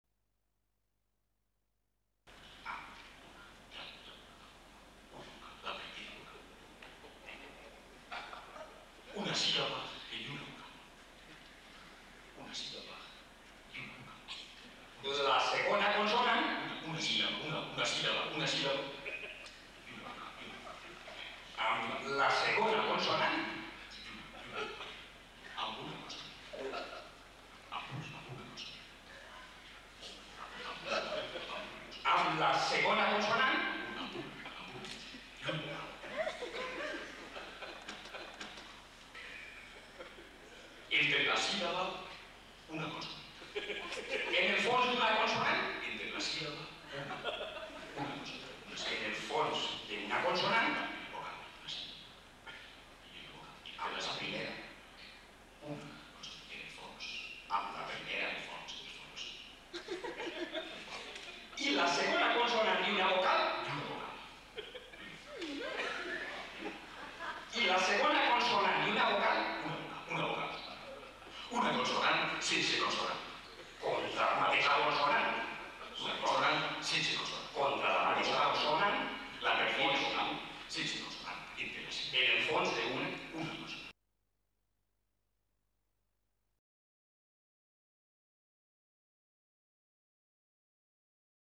Centro de Cultura Contemporania – Barcelona, 17 de abril de 1999.